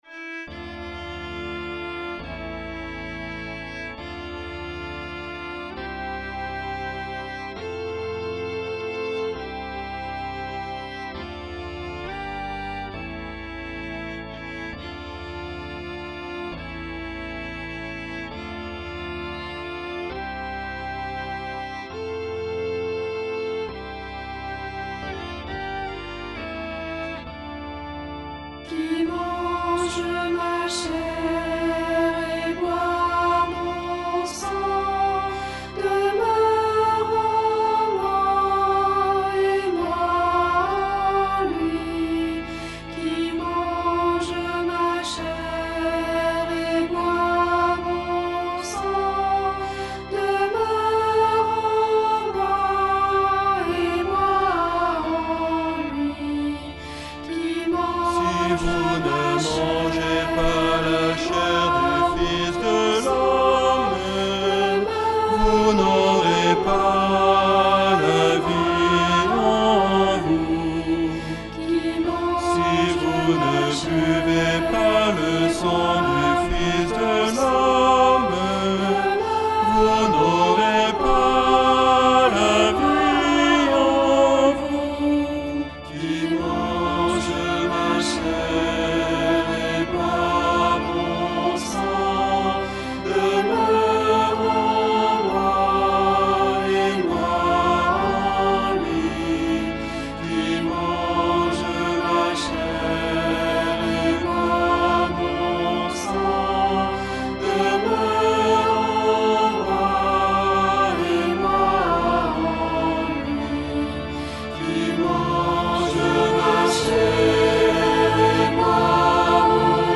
Orchestration incluant la méditation